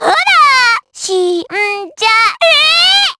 Pansirone-Vox_Skill6_jp.wav